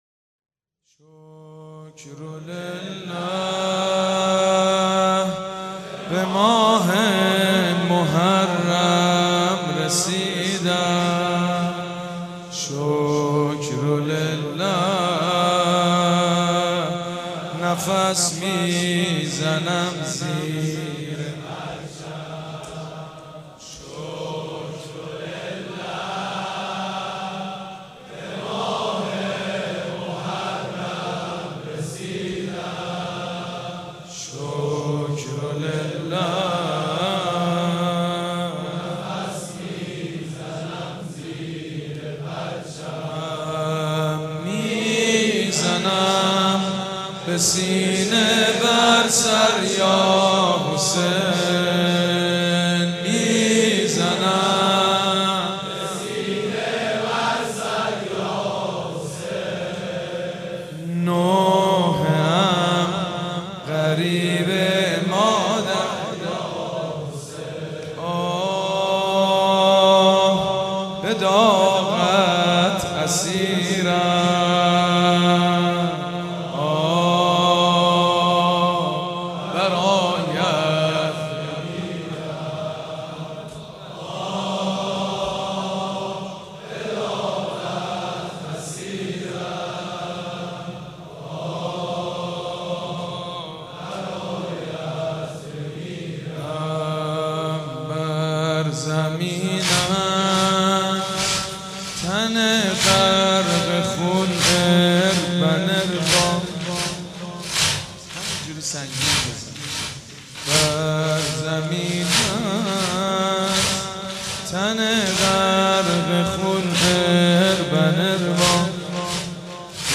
مجموعه مراسم بنی فاطمه در شب هشتم محرم 93
پسر لیلا وای وای قمر لیلا وای وای (زمینه زیبا)